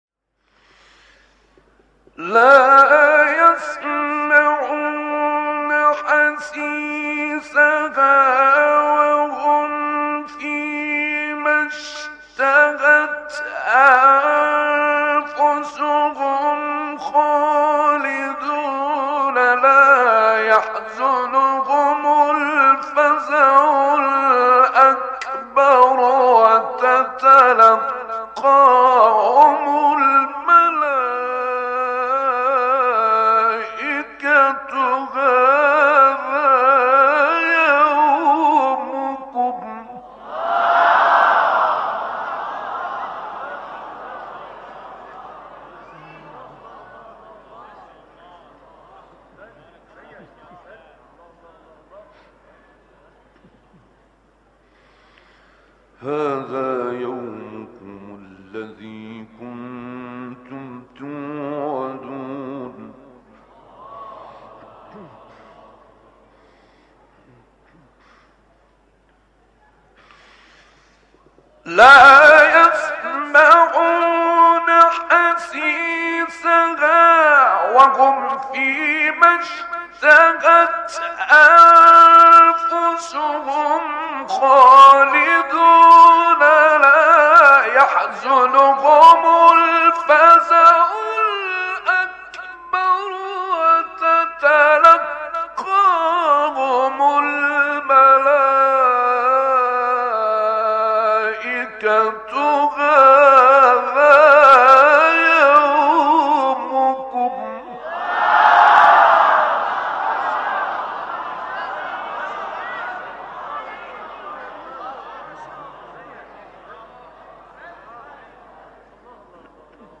آیه 102-111 سوره انبیاء استاد سید سعید | نغمات قرآن
سوره : انبیاء آیه: 102-111 استاد : سید سعید مقام : مرکب خوانی (رست * حجاز) قبلی بعدی